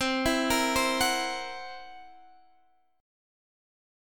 C7b5 Chord
Listen to C7b5 strummed